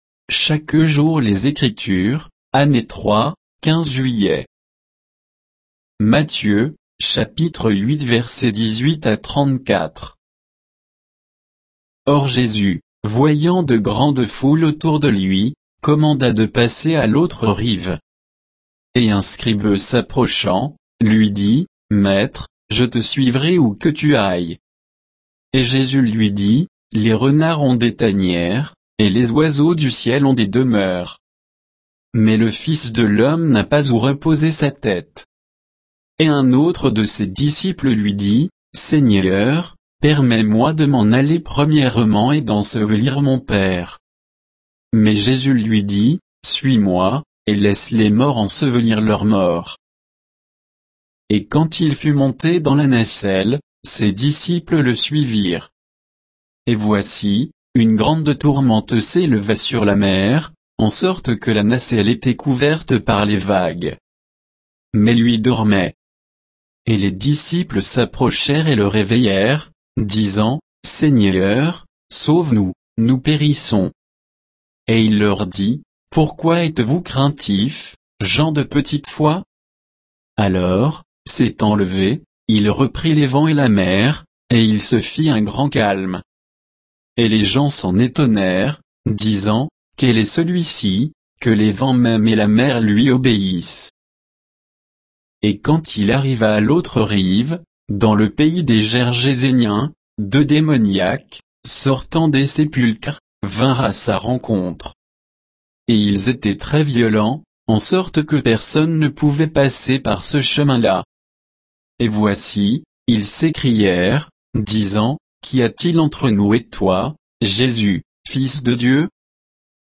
Méditation quoditienne de Chaque jour les Écritures sur Matthieu 8